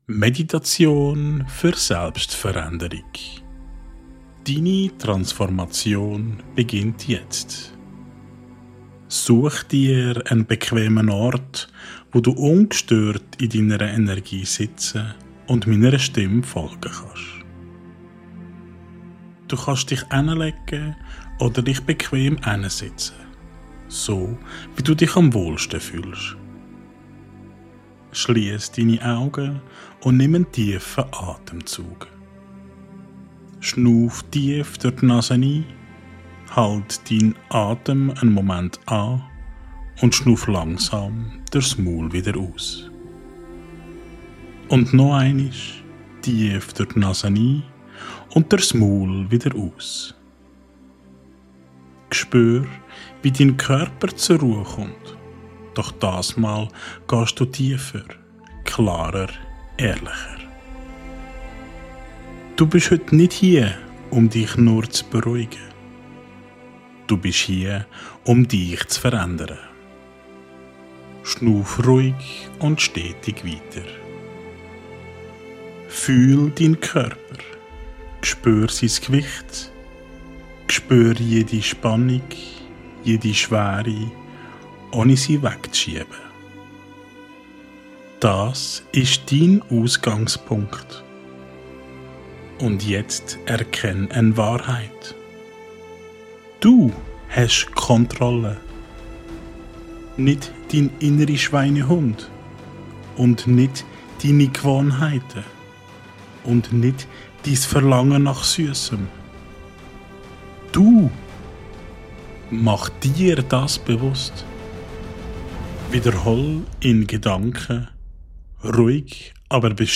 Beschreibung vor 2 Tagen Diese geführte Meditation ist für dich gemacht, wenn du abnehmen möchtest, dich aber immer wieder selbst blockierst – wenn dein innerer Schweinehund stärker ist als dein Wille.